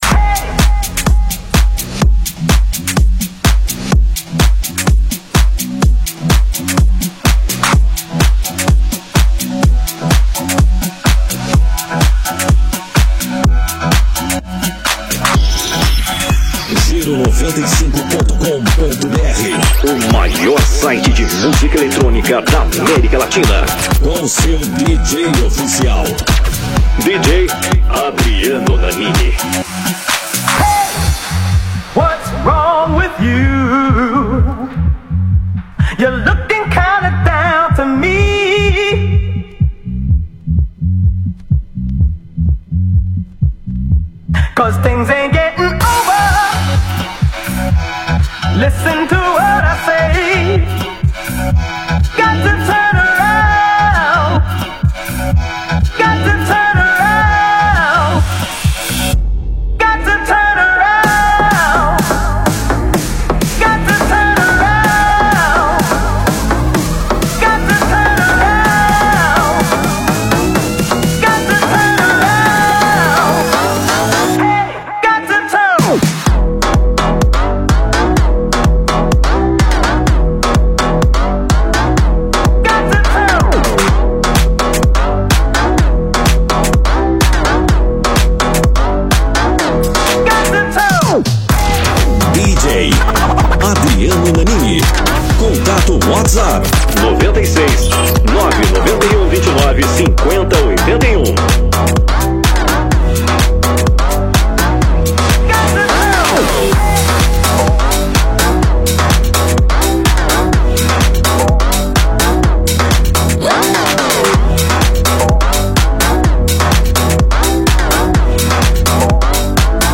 projeto mixado